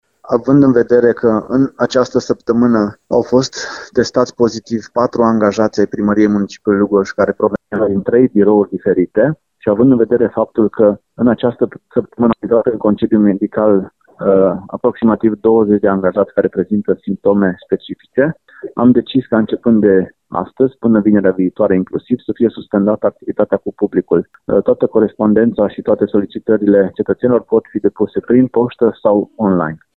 insert-claudiu-buciu.mp3